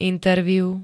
interview [intervjú] neskl. s.
Zvukové nahrávky niektorých slov